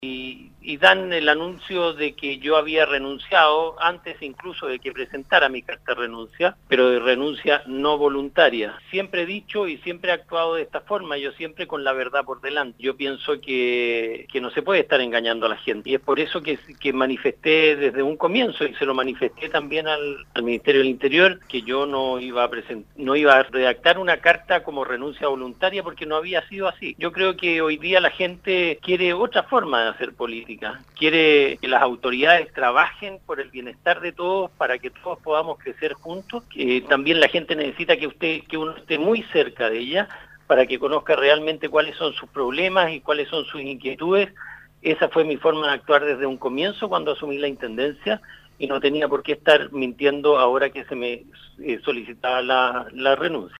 Christian Matheson en conversación con Radio SAGO cuenta quefue citado al palacio de gobierno por el ministro Chadwick, quien el martes a las 08.45 horas le solicitó que firme una renuncia voluntaria, a lo que el no accedió, porque además en ese momento el gobierno ya había comunicado su dimisión .